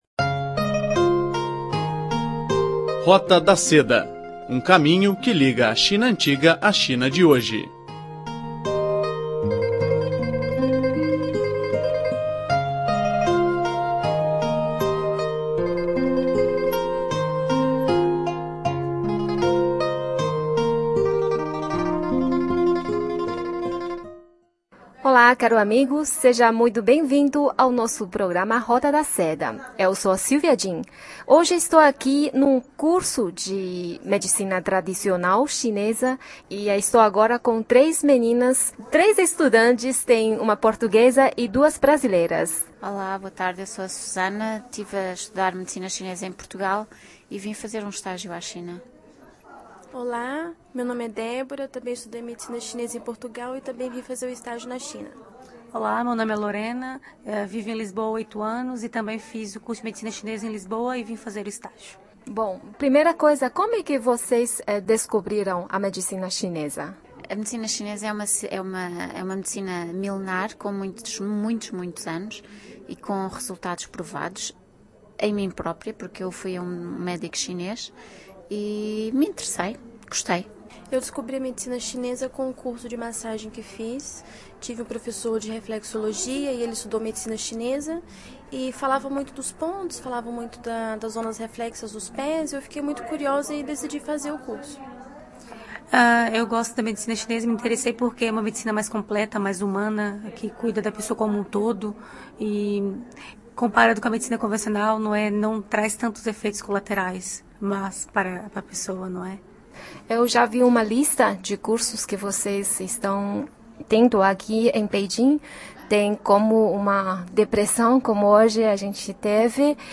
Entrevista com estudantes da acupuntura